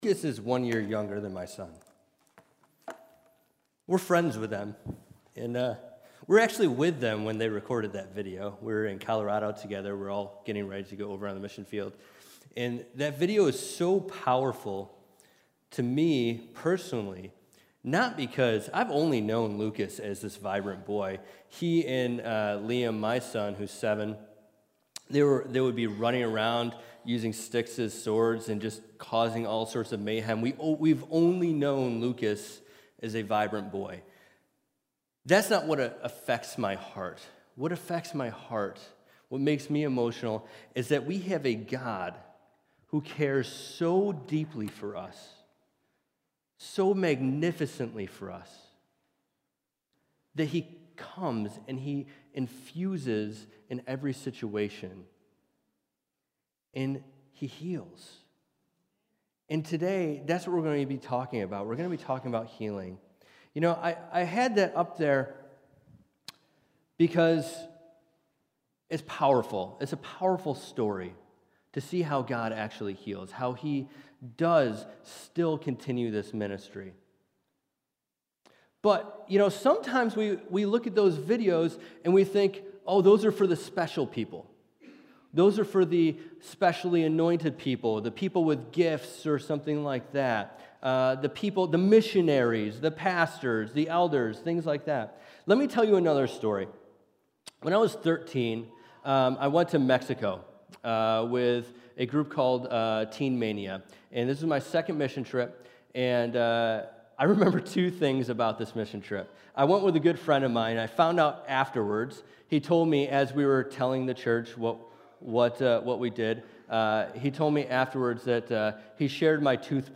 Sermons | Syracuse Alliance Church